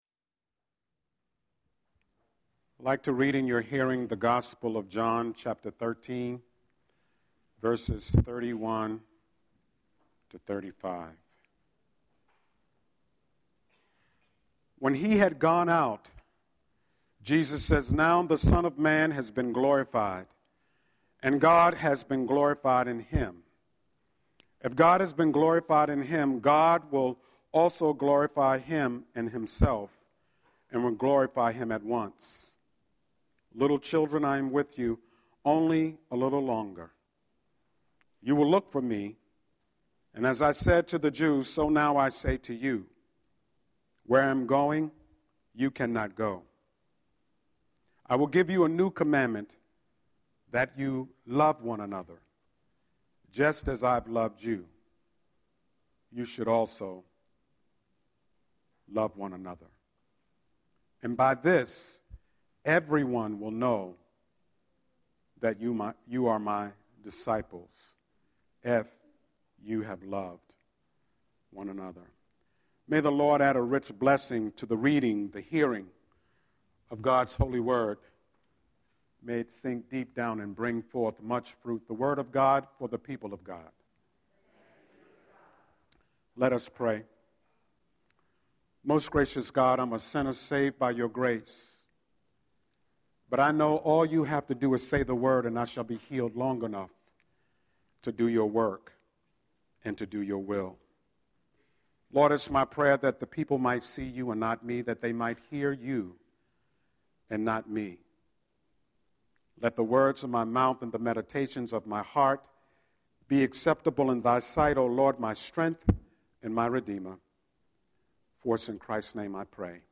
07-05-Scripture-and-Sermon.mp3